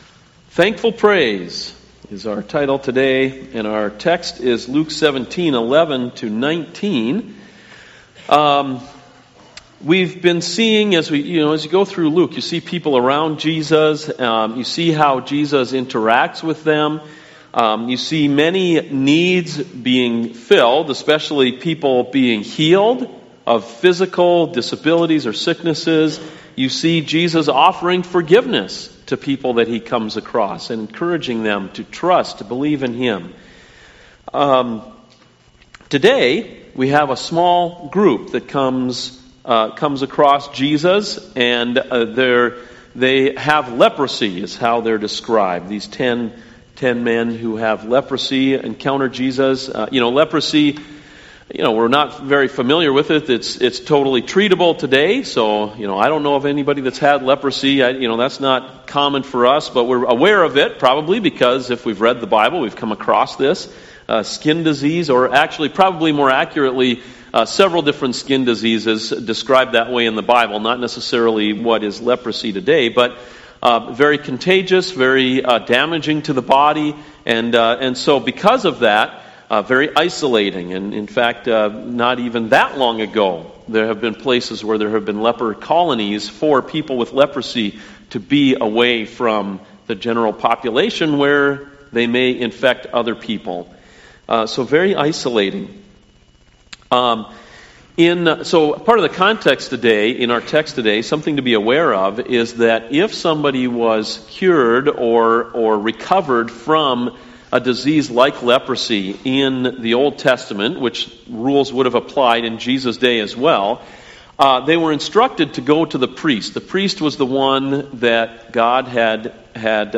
Sermons Thankful Praise (Luke 17:11-19)